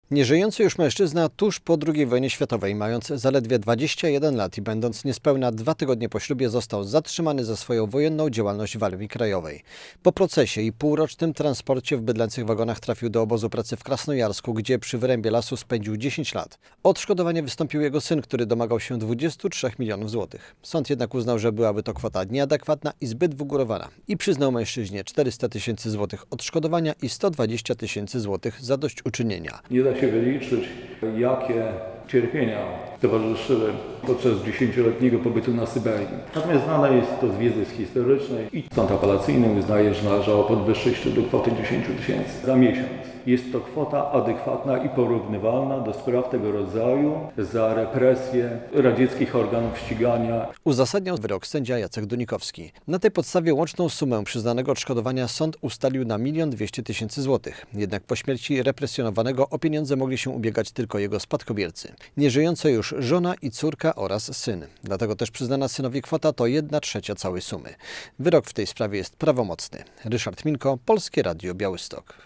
520 tysięcy złotych zadośćuczynienia i odszkodowania za zesłanie na Syberię - relacja